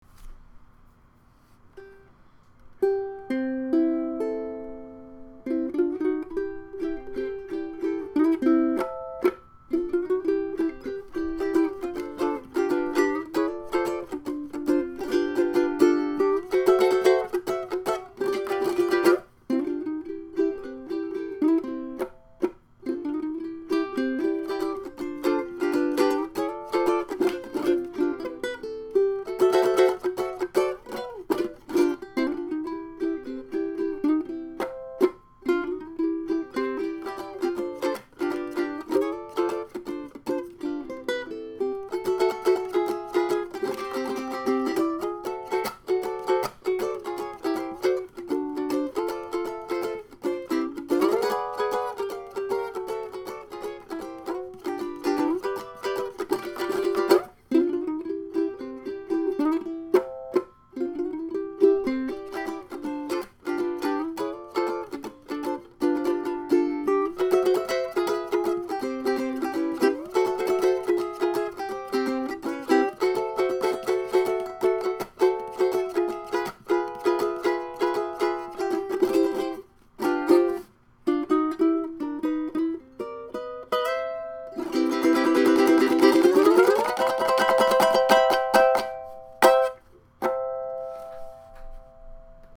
His set of wood was some incredible myrtle.
hula.m4a